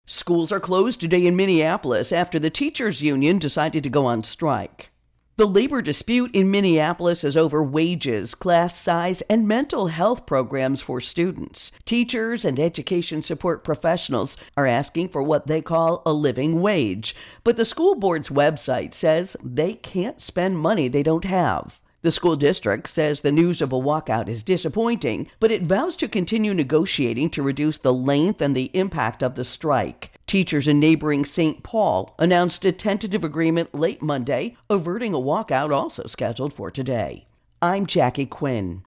Minnesota Schools Strike Schools Intro and Voicer